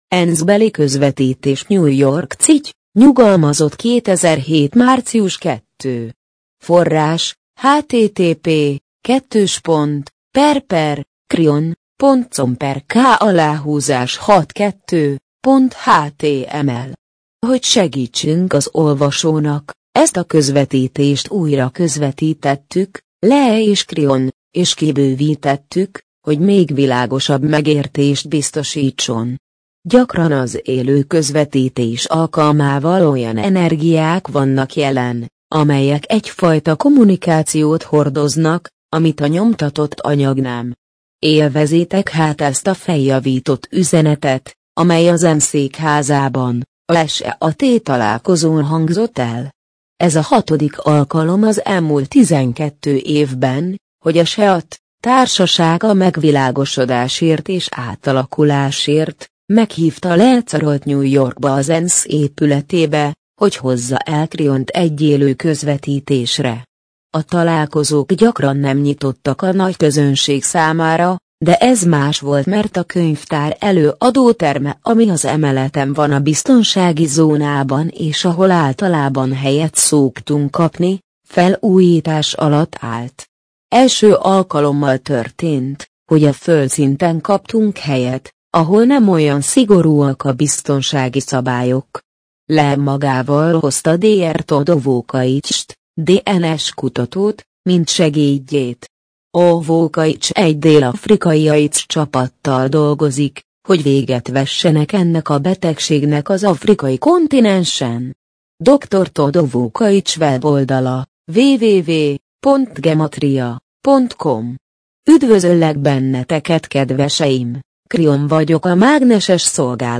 MP3 gépi felolvasás ENSZ-beli közvetítés - 2007 ENSZ-beli közvetítés New York City, NY - 2007. március 2.